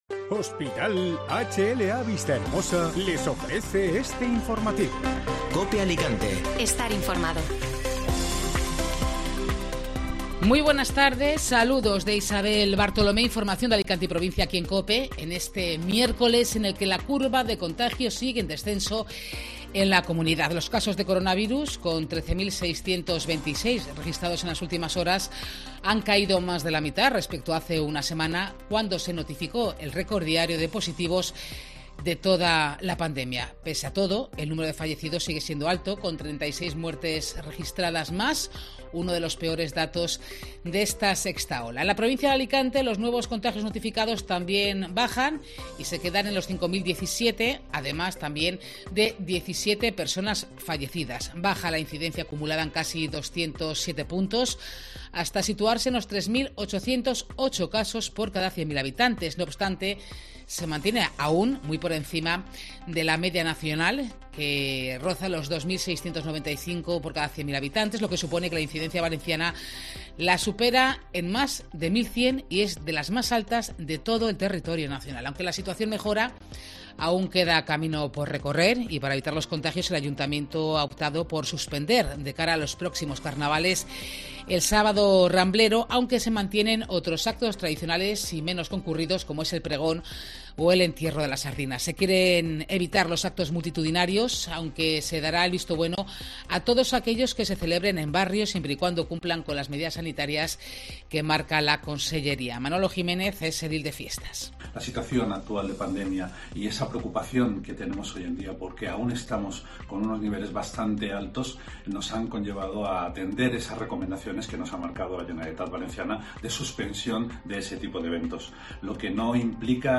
Informativo Mediodía COPE Alicante (Miércoles 2 de febrero)